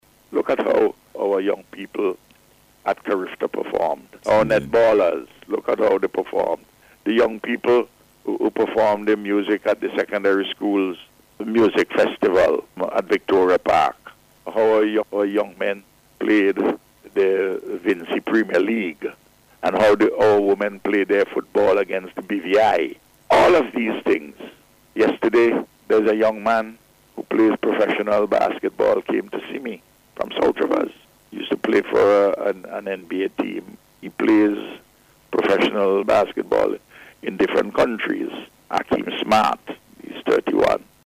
He made this commendation during the Face to Face program aired on NBC Radio on Wednesday as he updated the country on matters of national development.